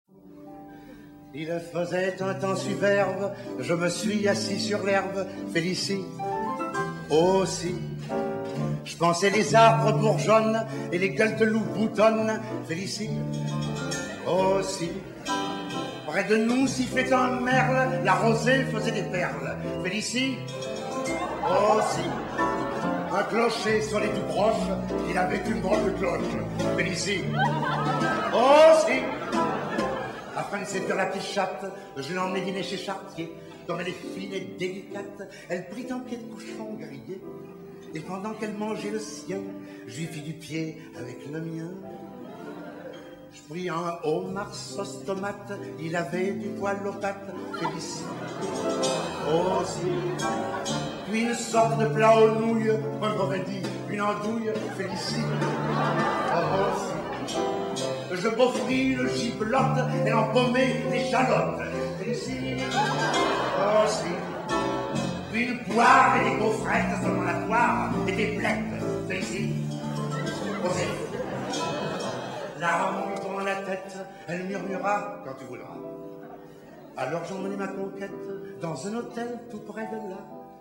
Chœur d’hommes fondé en 1860
Pierre Hüwiler (1948·2019)
Interprété par le Chœur du Léman en : 2016, 2022
H10447-Live.mp3